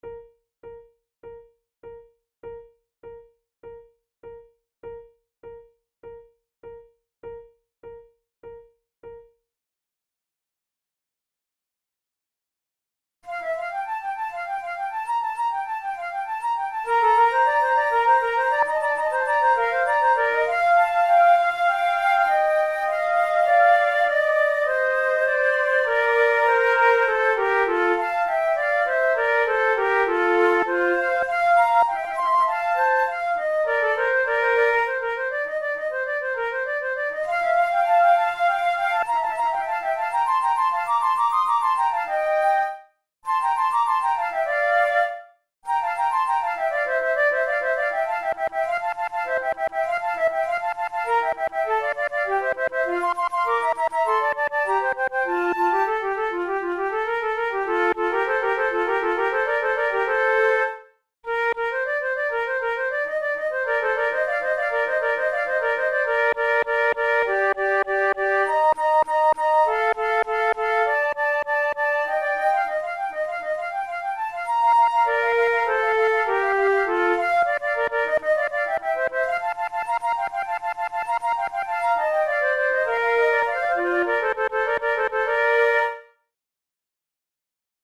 KeyB-flat major
Tempo100 BPM
Baroque, Sonatas, Written for Flute